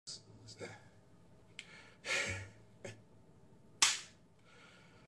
Satisfying AI ASMR Pimple Popper!